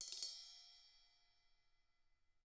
Index of /90_sSampleCDs/ILIO - Double Platinum Drums 2/Partition H/CYMBALRUFFSD